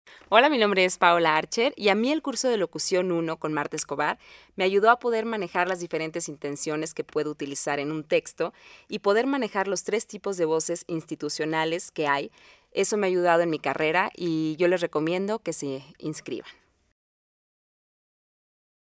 Locución Comercial